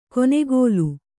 ♪ konegōlu